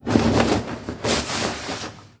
household
Cloth Shake Smooth